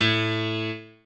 piano5_2.ogg